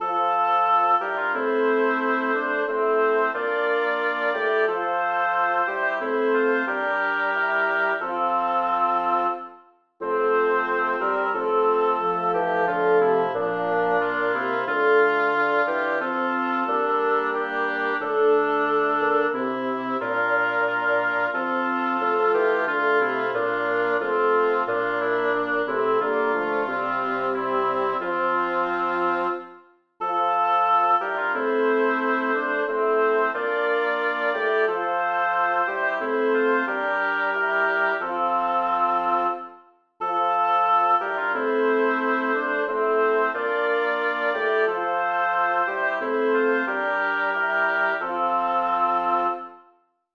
Number of voices: 4vv Voicing: SATB Genre: Sacred, Villancico
Language: Spanish Instruments: A cappella